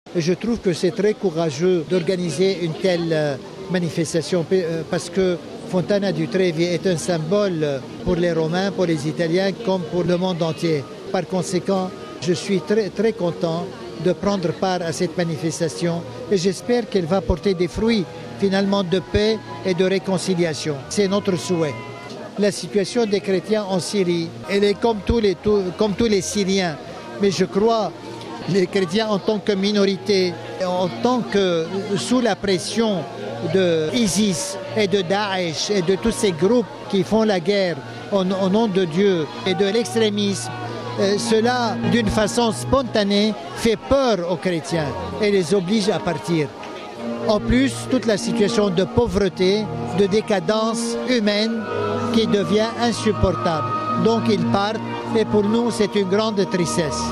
RV Entretien - La Fontaine de Trevi, au cœur de Rome, s’est teintée de rouge, ce vendredi 29 avril, pour symboliser le sang des chrétiens persécutés dans le monde entier.